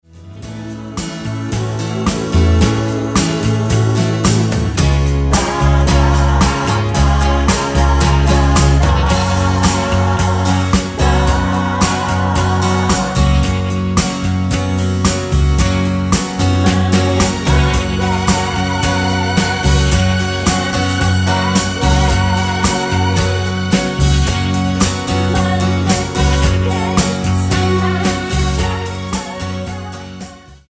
Tonart:F#-G# mit Chor
Die besten Playbacks Instrumentals und Karaoke Versionen .